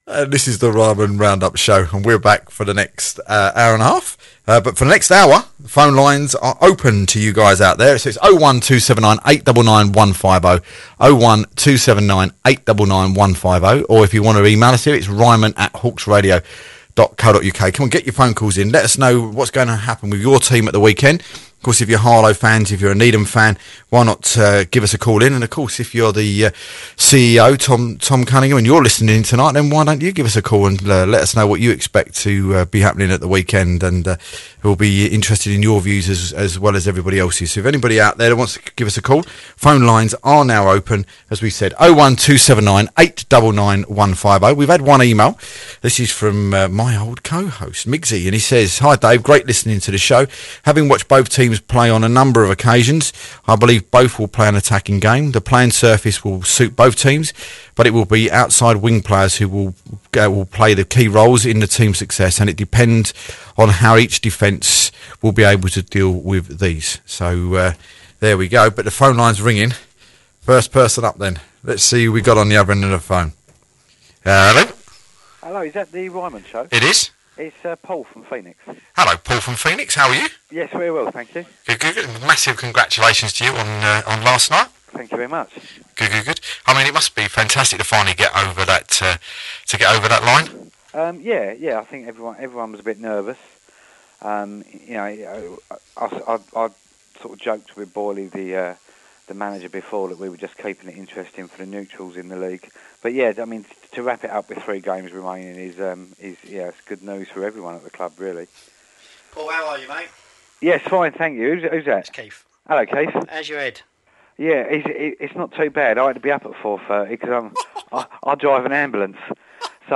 Ryman Round Up Show Season Two 16.04.15 Fans Phone In